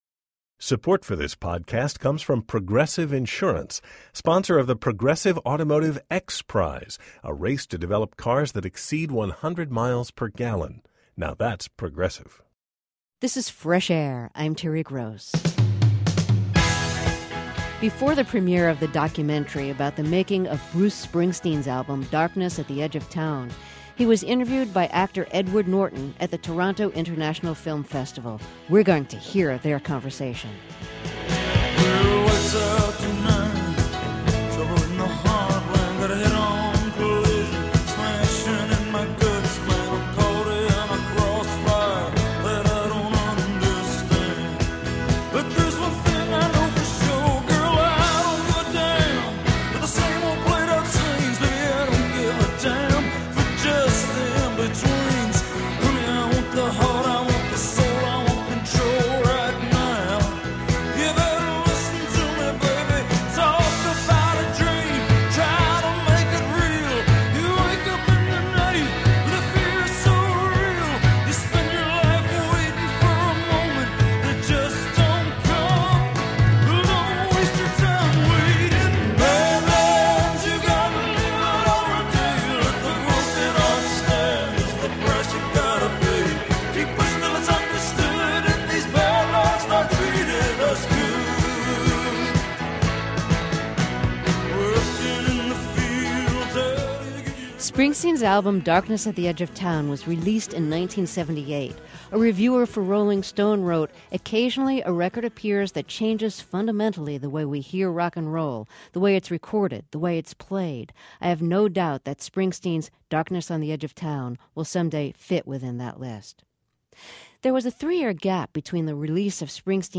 01 Ed Norton Interviews Bruce Spring